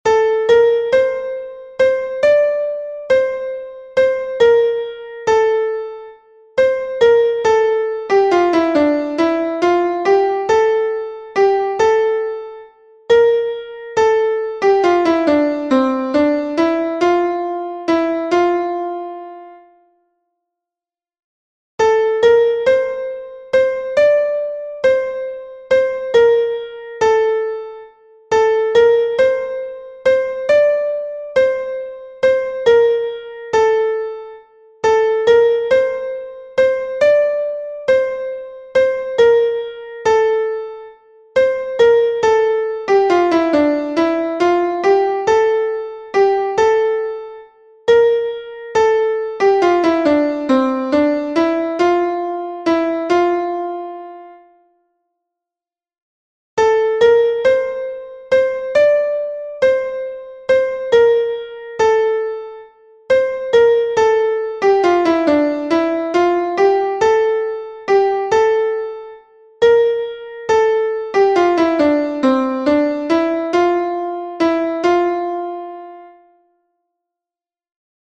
Solos Fichiers MP3
Fichier son Soprano